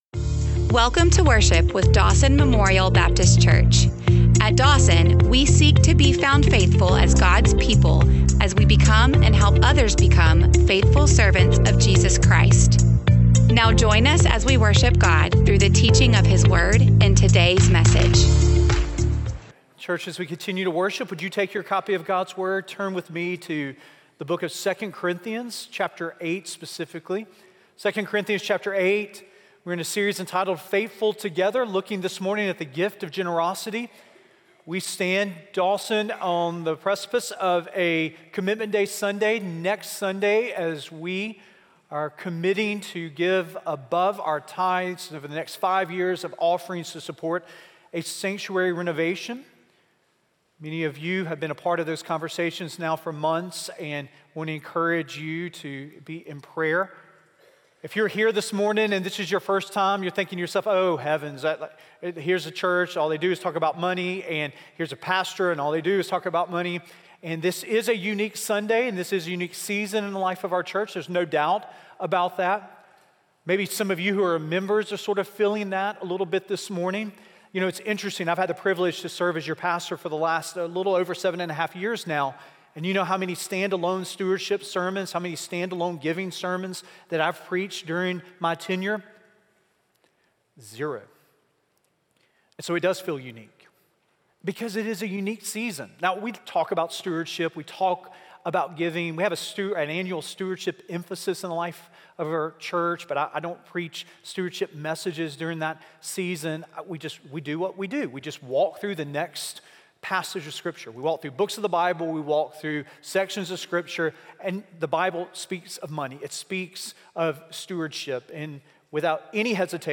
Teaching sermons presented during Sunday morning worship experiences with the Dawson Family of Faith, Birmingham, Alabama.